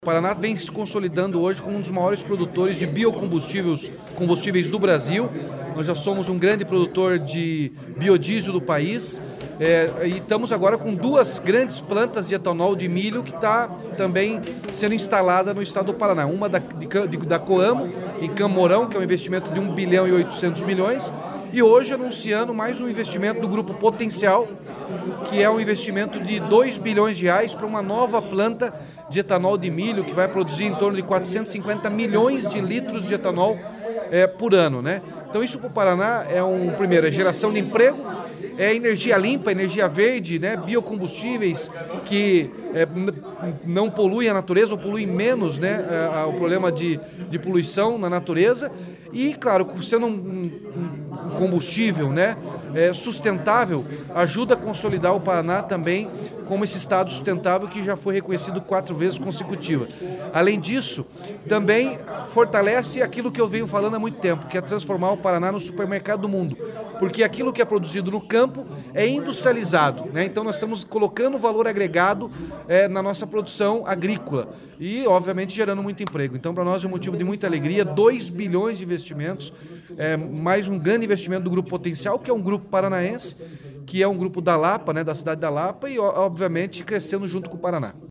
Sonora do governador Ratinho Junior sobre o investimento do Grupo Potencial em uma biorrefinaria de etanol de milho na Lapa